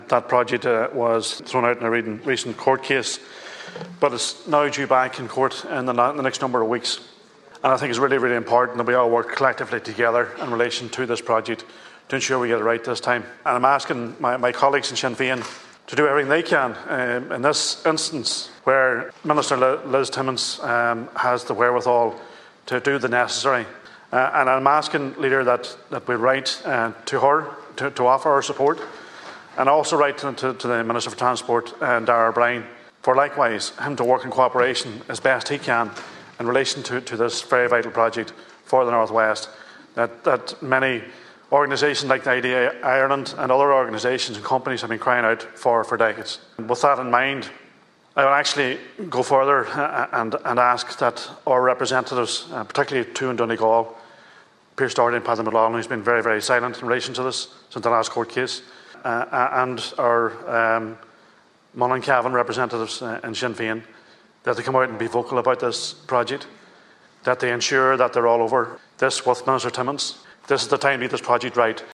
On the Order of Business in the Seanad this morning, Senator Niall Blaney claimed Sinn Fein have been silent on the A5 locally, and with an appeal to June’s court decision to be held next month, now is the time to be vocal…………